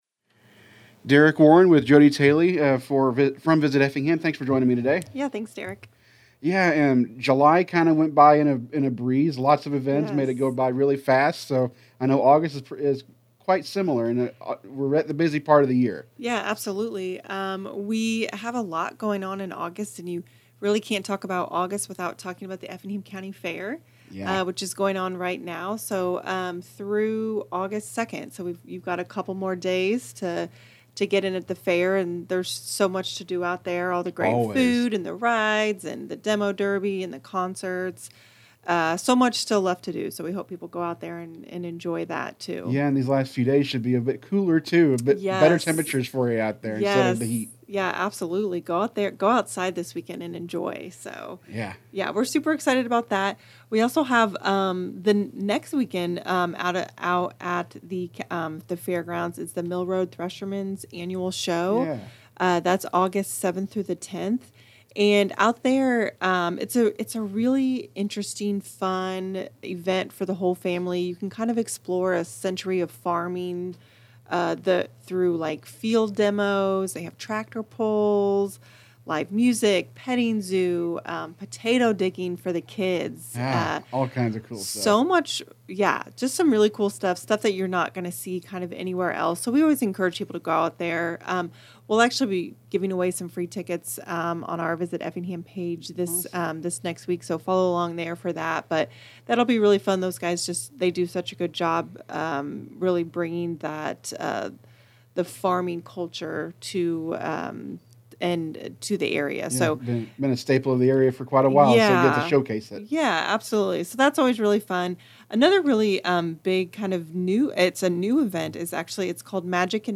This is part of a monthly series of interviews that will continue next month.